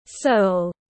Lòng bàn chân tiếng anh gọi là sole, phiên âm tiếng anh đọc là /səʊl/.